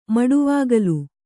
♪ maḍuvāgalu